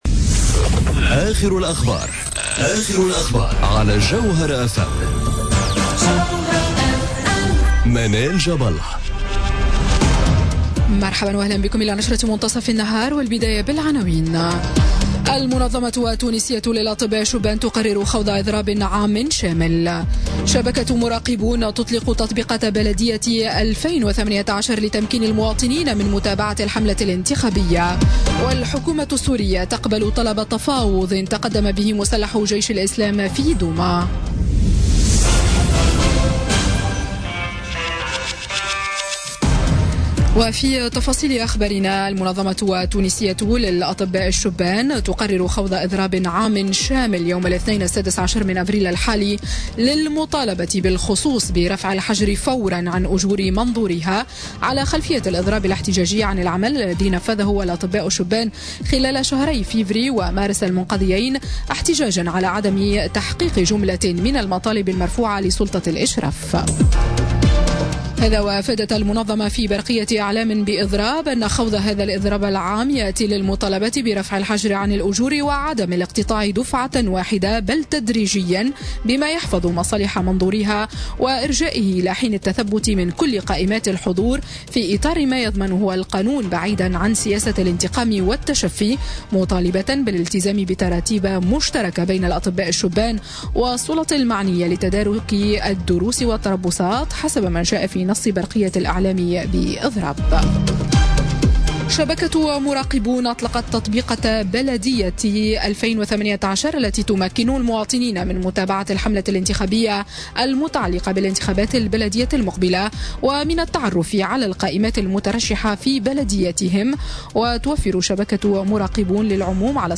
نشرة أخبار منتصف النهار ليوم الأحد 8 أفريل 2018